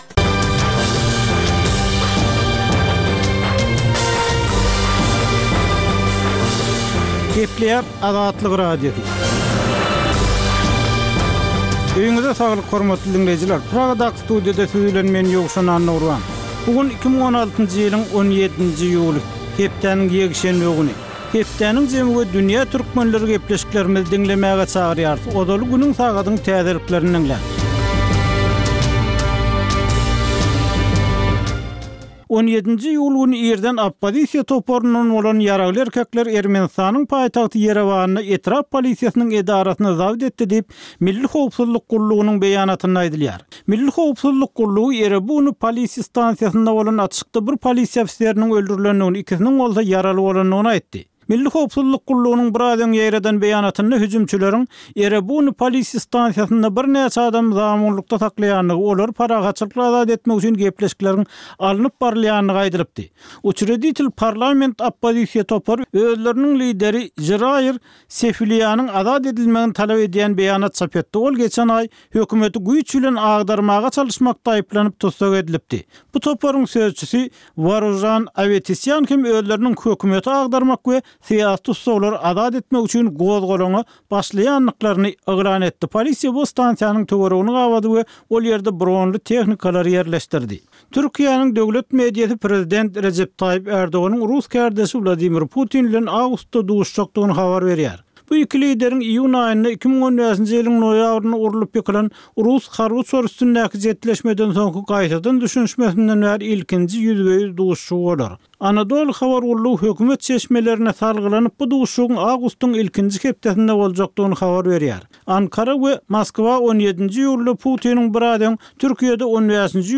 Tutuş geçen bir hepdäniň dowamynda Türkmenistanda we halkara arenasynda bolup geçen möhüm wakalara syn. Bu ýörite programmanyň dowamynda hepdäniň möhüm wakalary barada synlar, analizler, söhbetdeşlikler we kommentariýalar berilýär.